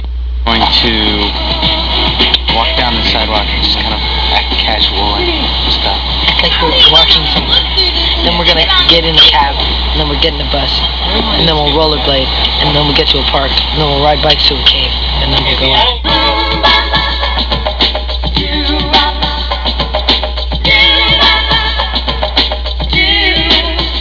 MMMBop Video This is the part where Isaac and Taylor are explaining what they are going to do in the MMMBop music video.